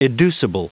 Prononciation du mot educible en anglais (fichier audio)
Prononciation du mot : educible